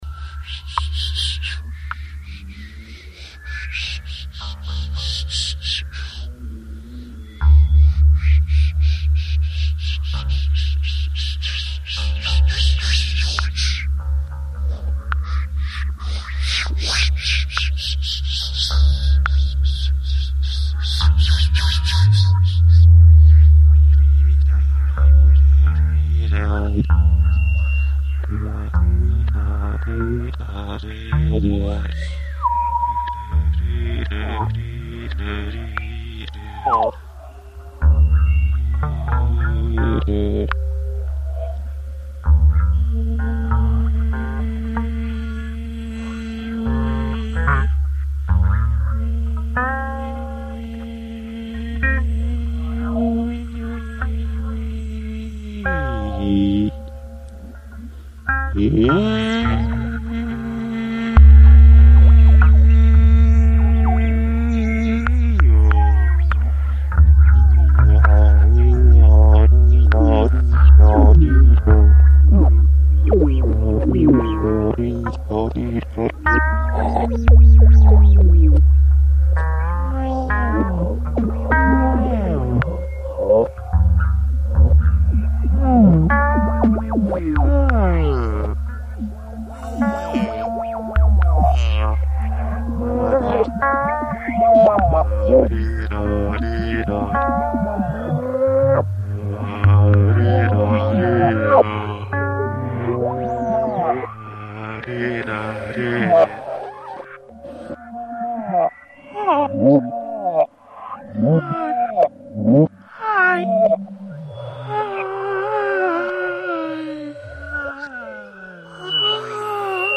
Here is a composition I made out of voice and lute filtered by the Duber: